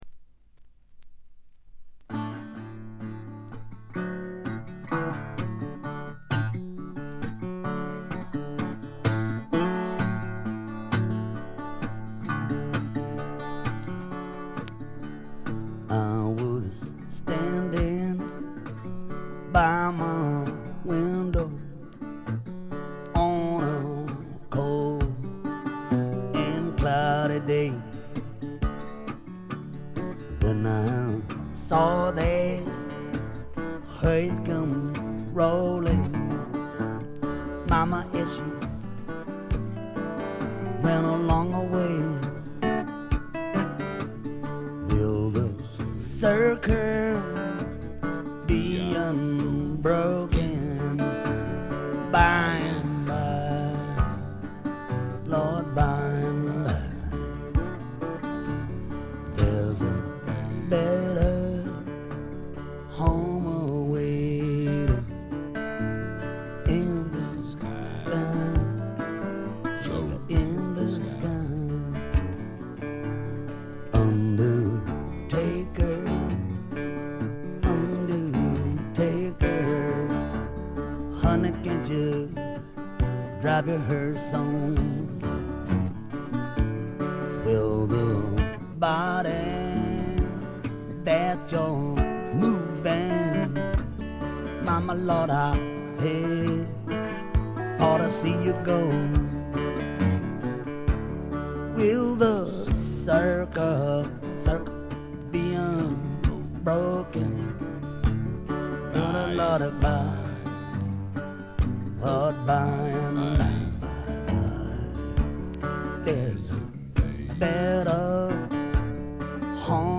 部分試聴ですがほとんどノイズ感無し。
内容は激渋のアコースティック・ブルース。
試聴曲は現品からの取り込み音源です。